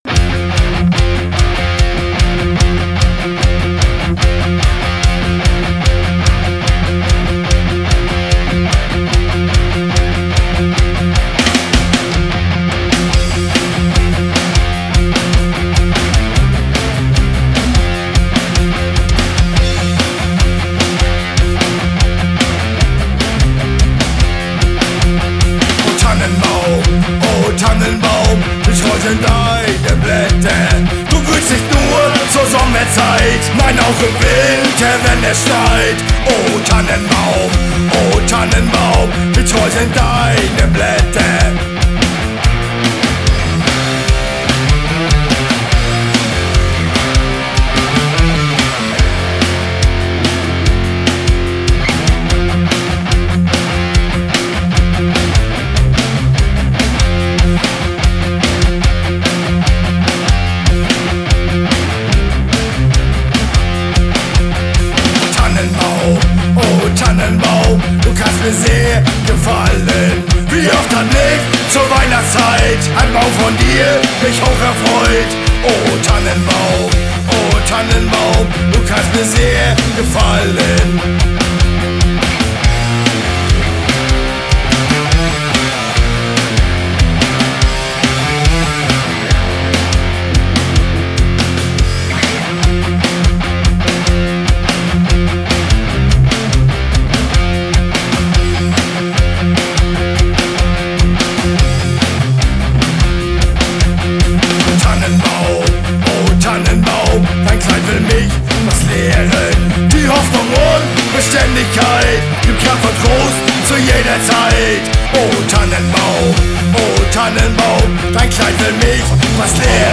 Musikalische Julgrüße von der Küste…